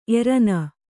♪ erana